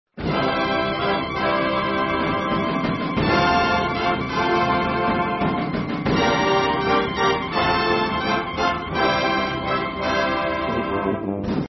Click foto for their salute to Monkey.